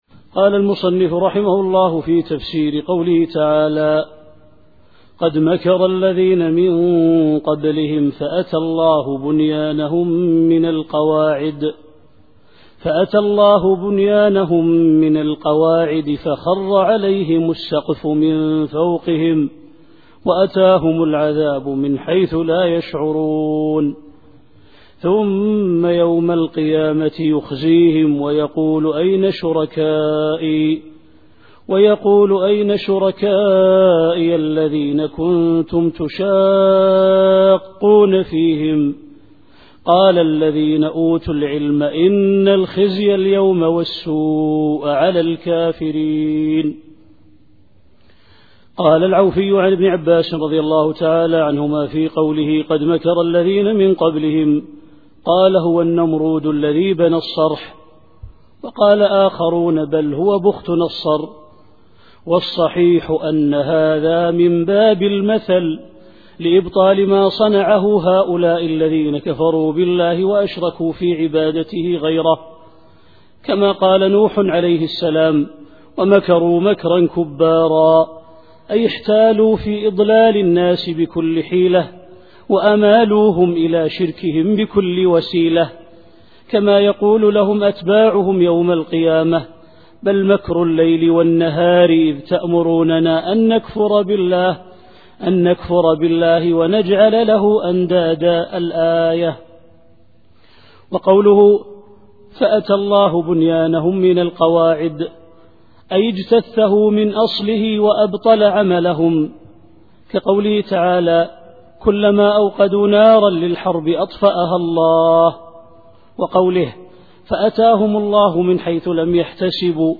التفسير الصوتي [النحل / 26]